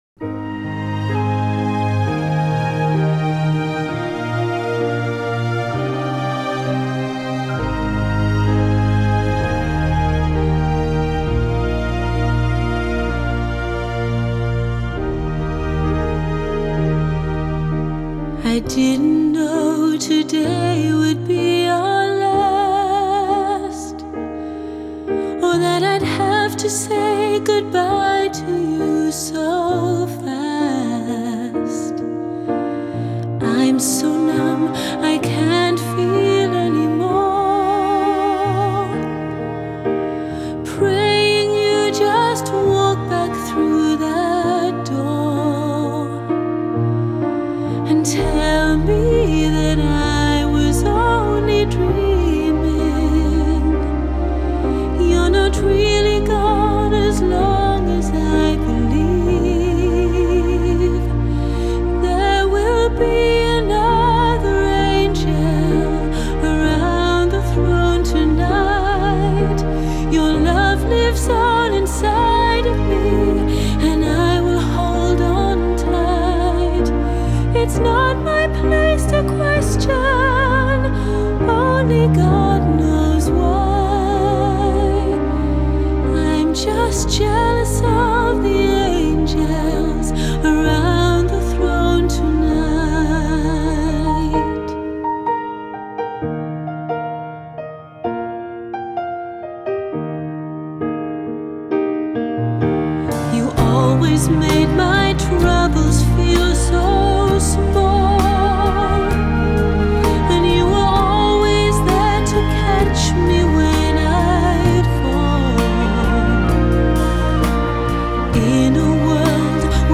Crossover